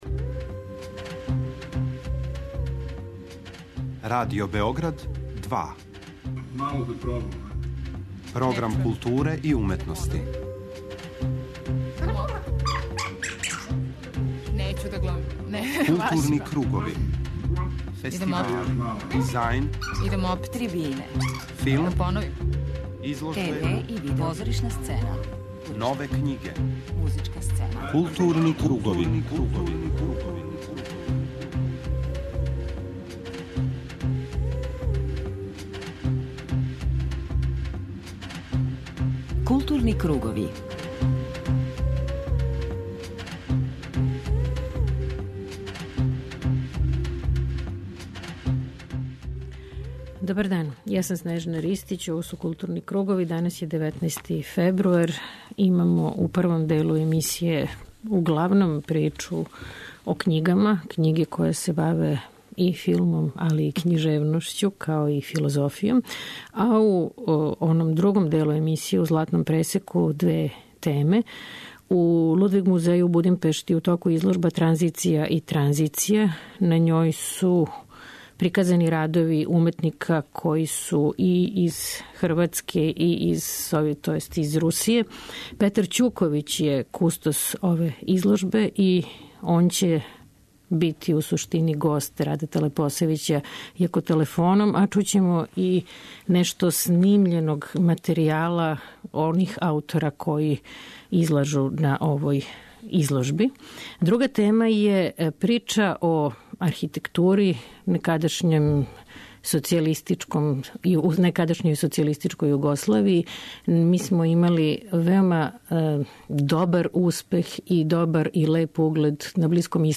преузми : 53.23 MB Културни кругови Autor: Група аутора Централна културно-уметничка емисија Радио Београда 2.